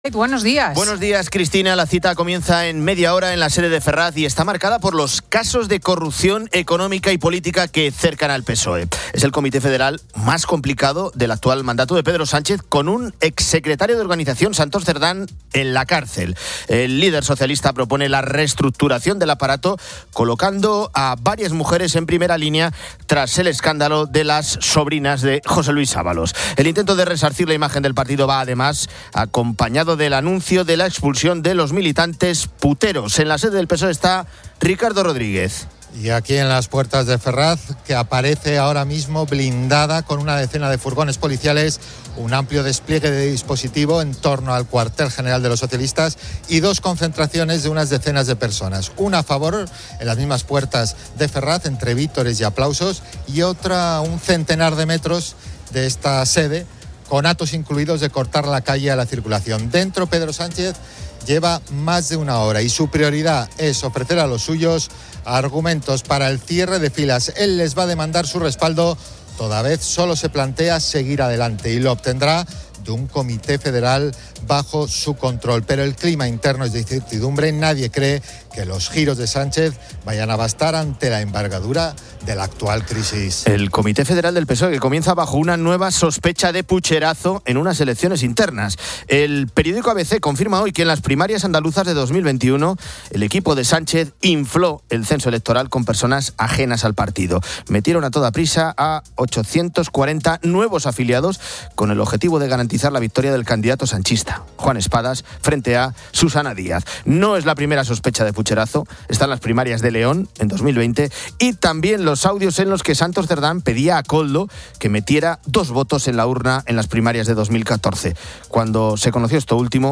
Fin de Semana 10:00H | 05 JUL 2025 | Fin de Semana Editorial de Cristina López Schlichting. Hablamos con Elías Bendodo, vicesecretario de Coordinación Autonómica y Local del PP. Nos damos una vuelta por Europa para conocer como están viviendo estos calores.